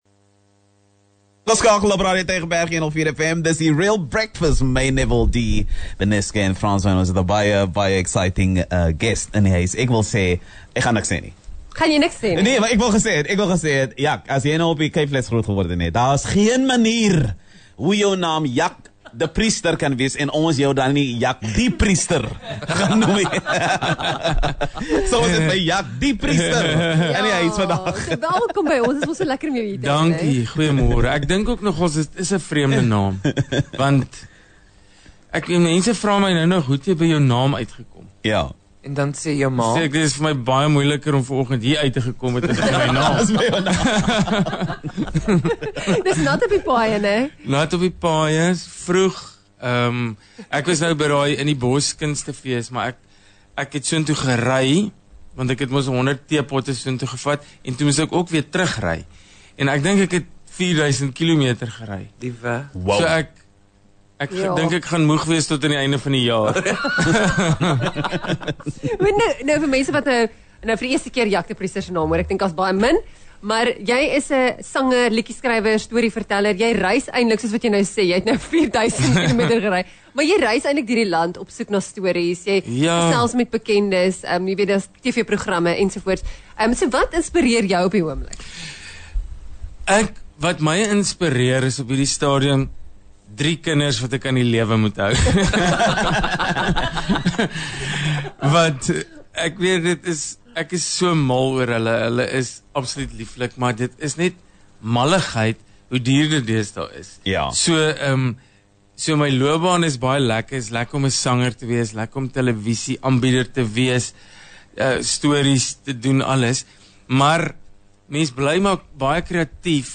10 Jul Onderhoud met Jak de Priester_Die Real Brekfis (06:00-09:00)